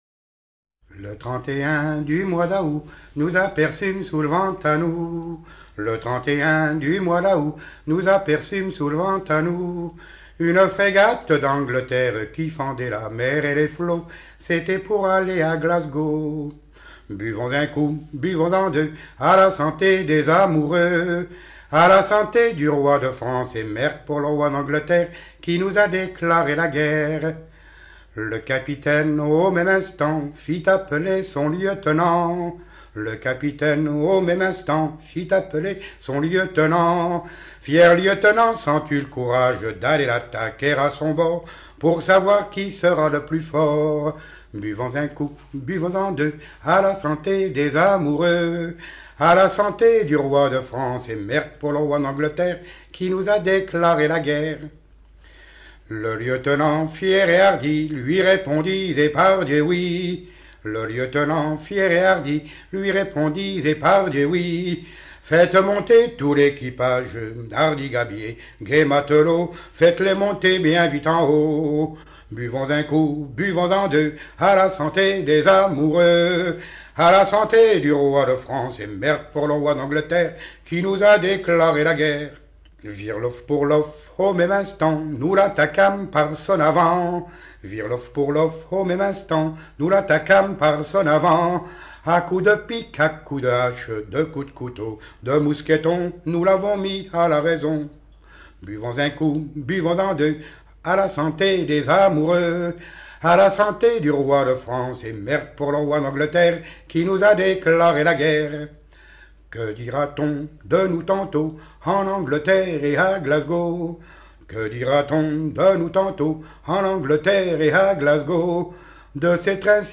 circonstance : maritimes
Genre strophique
Pièce musicale éditée